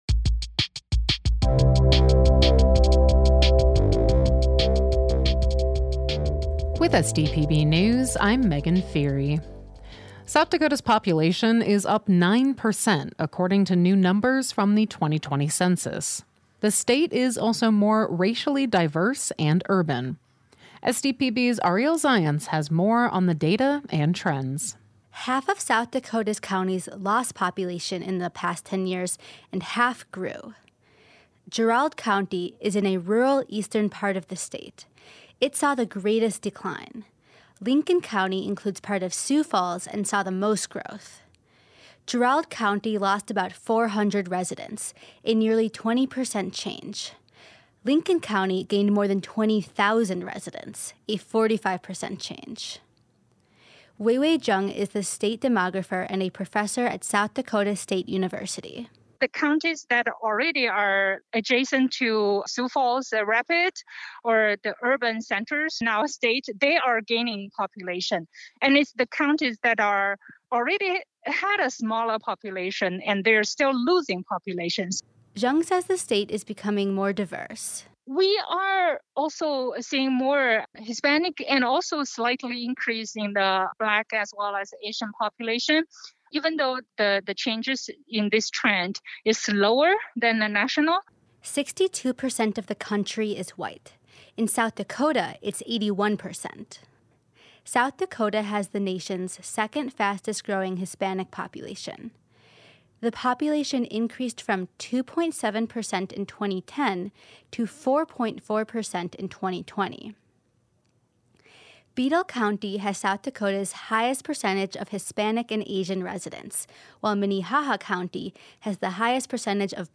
Each day, SDPB's journalism team works to bring you pertinent news coverage.